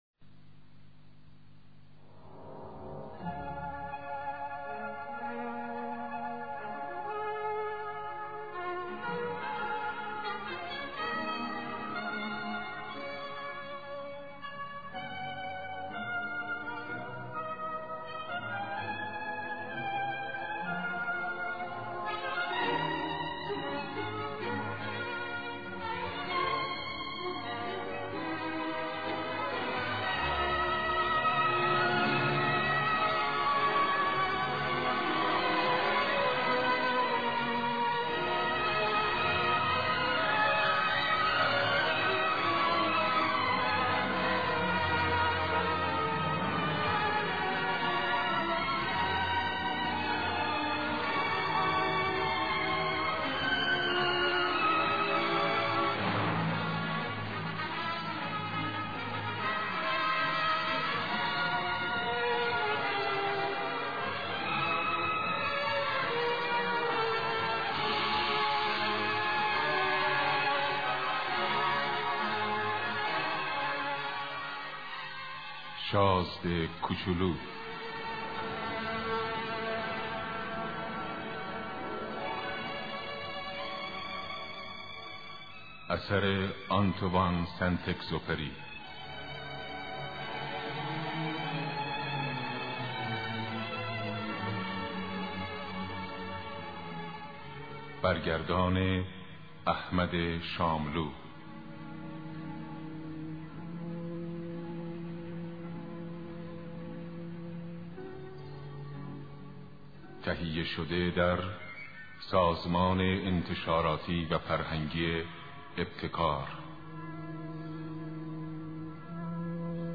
در این مطلب کتاب صوتی این رمان زیبا را با اجرا و صدای زیبای احمد شاملو برای شما آماده کرده ایم.